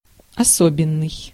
Ääntäminen
IPA: [spe.sjal]